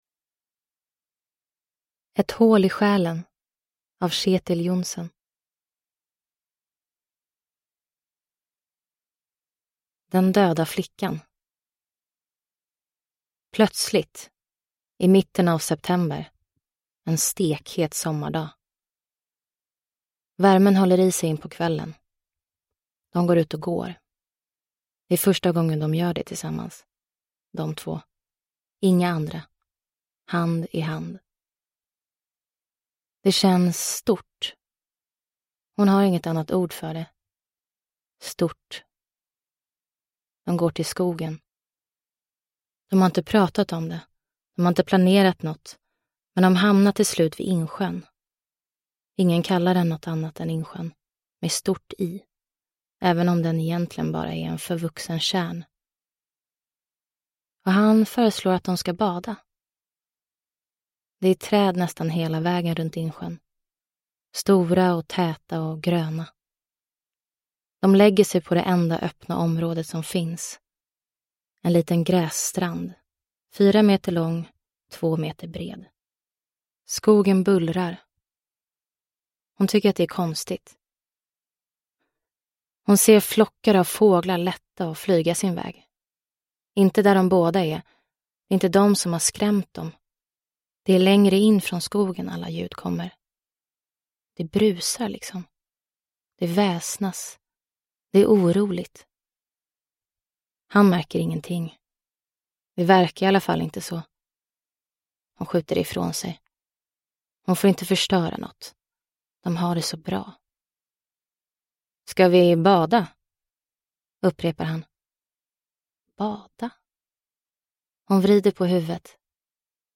Ett hål i själen – Ljudbok – Laddas ner